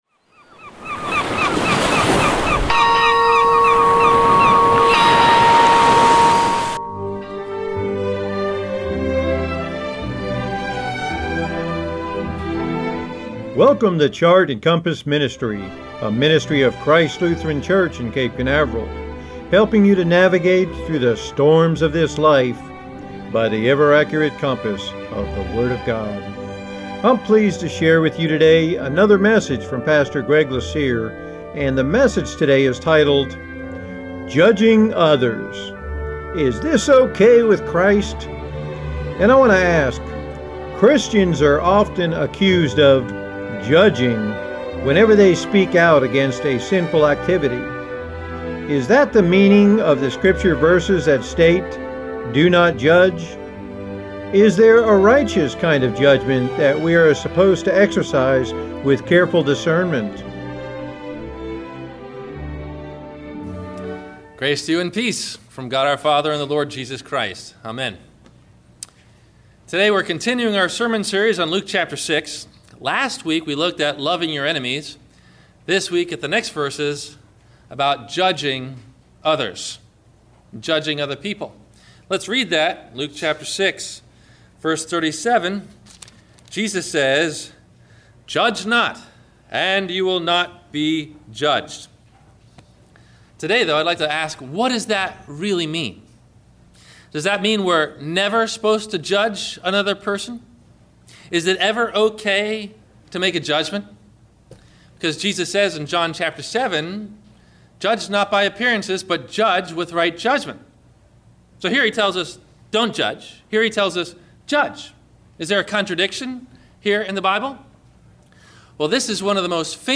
Have a Comment or Question about the Sermon?